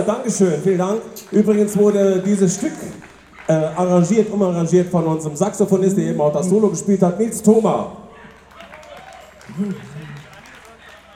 08 - Ansage.mp3